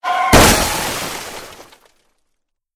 Аварии есть еще такой звук ДТП